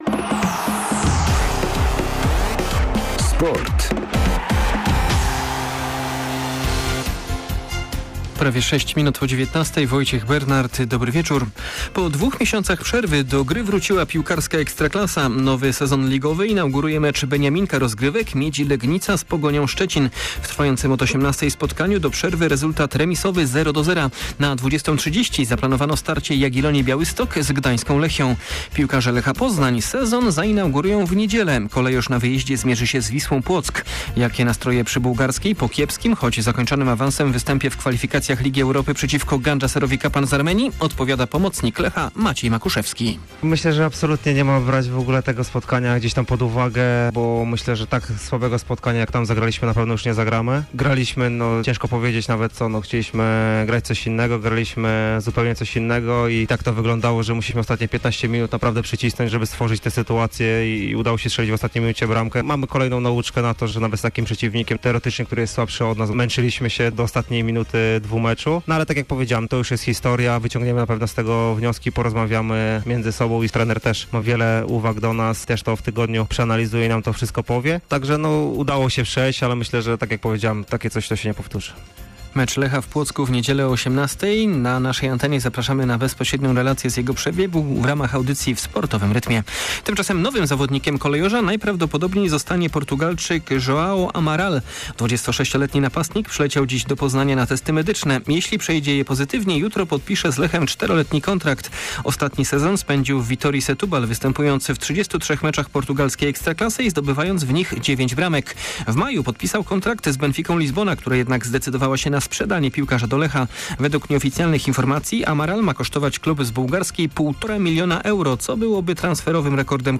20.07 serwis sportowy godz. 19:05
O nastrojach w Lechu Poznań przed inauguracją ligowych występów opowie pomocnik Kolejorza - Maciej Makuszewski.